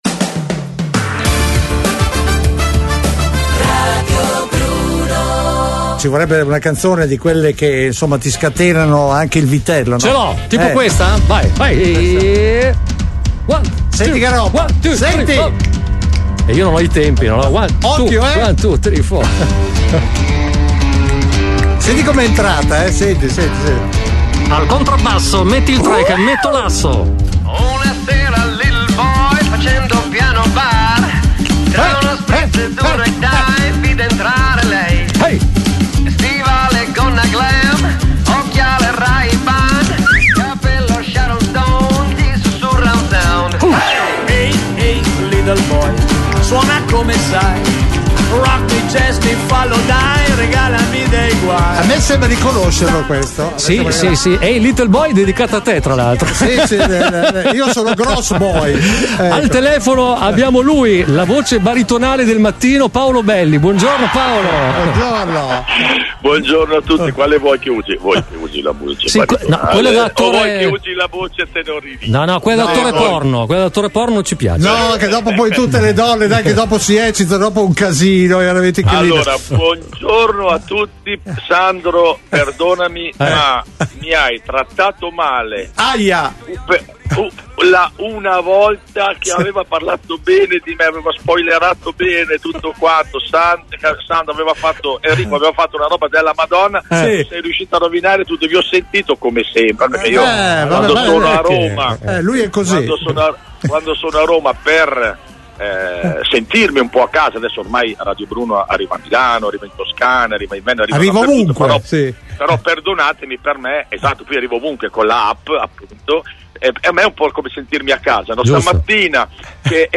Nel meglio del peggio della settimana, La Strana Coppia è al telefono Paolo Belli per la ripresa di Ballando con le Stelle.